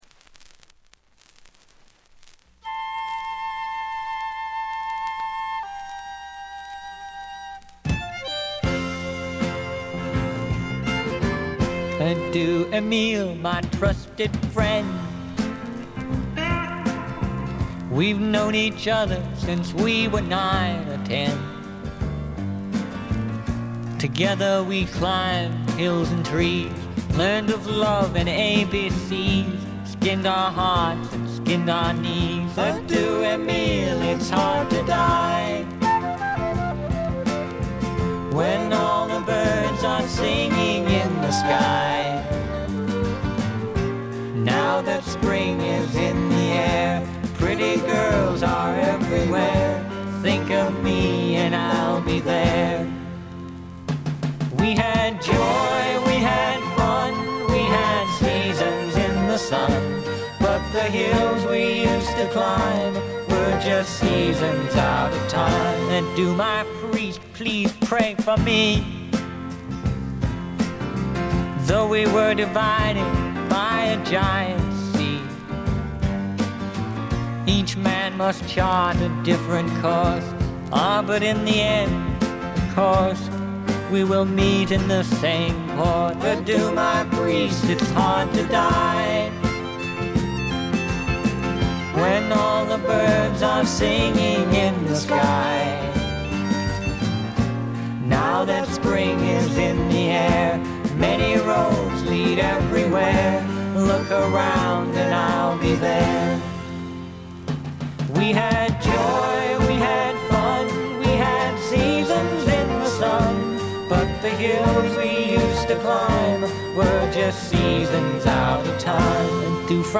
静音部で低いバックグラウンドノイズ。目立つノイズはありません。
試聴曲は現品からの取り込み音源です。